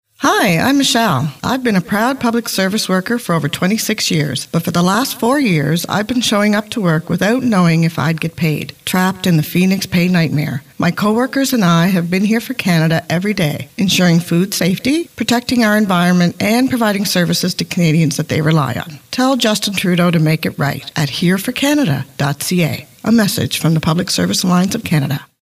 Annonce radio Î.-P.-É.599.13 Ko